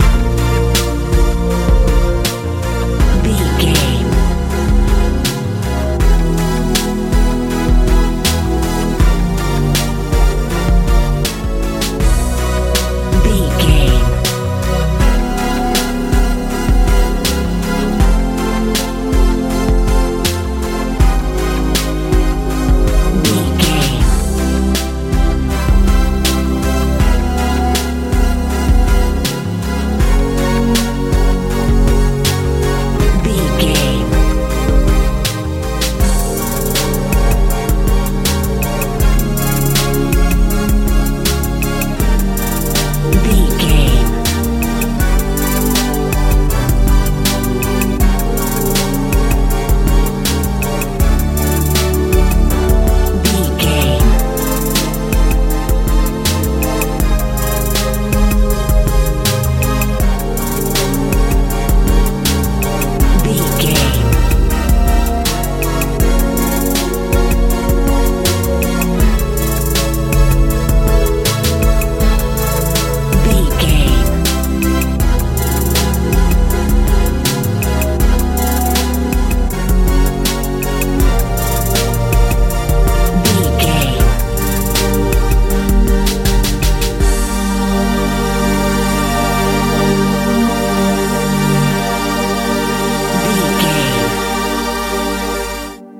modern pop feel
Ionian/Major
D♭
cool
hopeful
synthesiser
bass guitar
drums
80s
90s